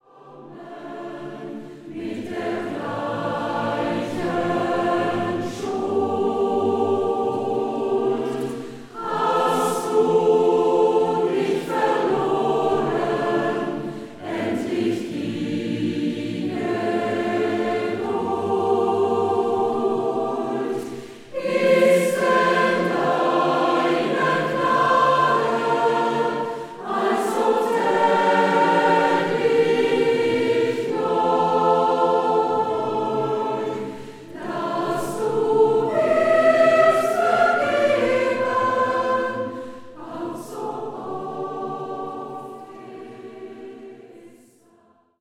• kurzweilige Zusammenstellung verschiedener Live-Aufnahmen